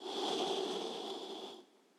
SFX_Door_Slide_02.wav